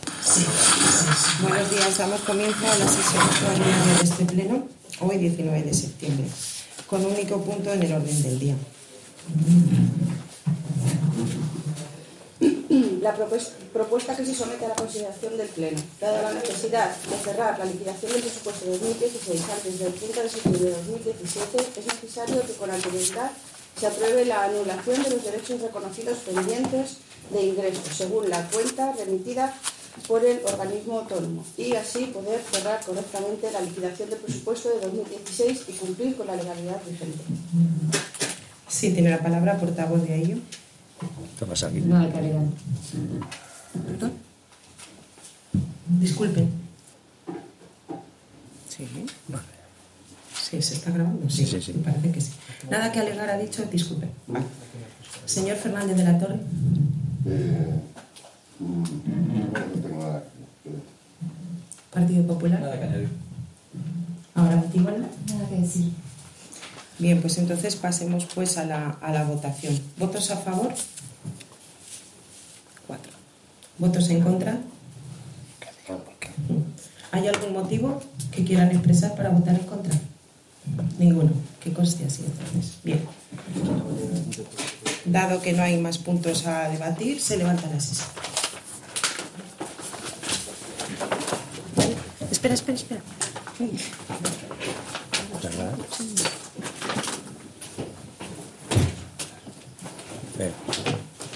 Audio del Pleno Extraordinario de 19 de septiembre de 2017